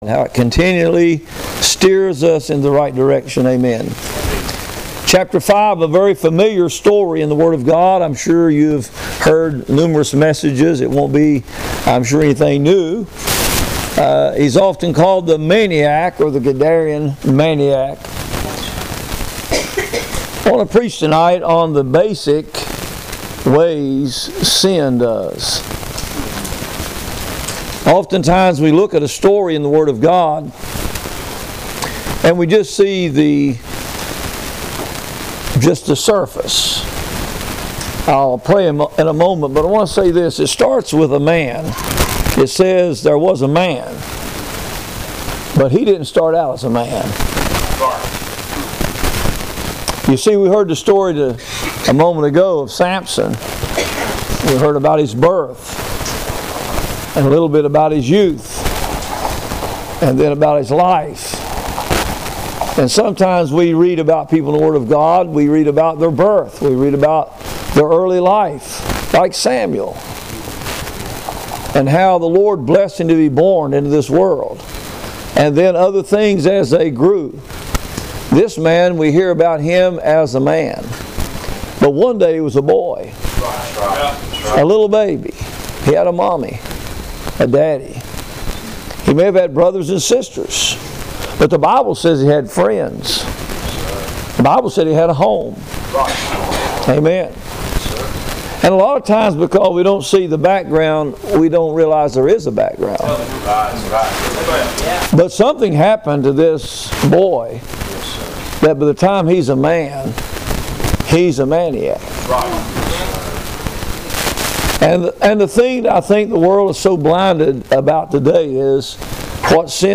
Sermons from Hannahstown Road Baptist Church